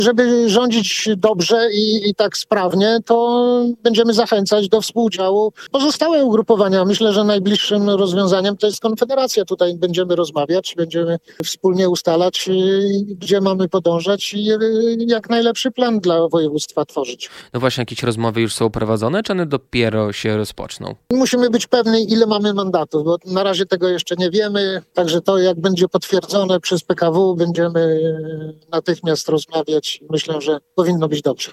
Z informacji, które do nas spływają wynika, że mamy większość w sejmiku – mówił na naszej antenie wicemarszałek województwa podlaskiego Marek Olbryś.